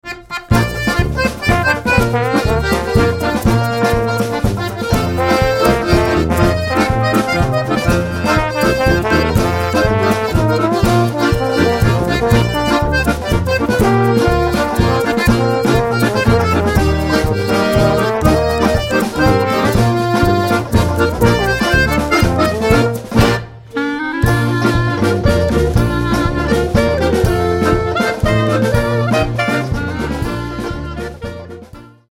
these young musicians still prefer natural, handmade sounds.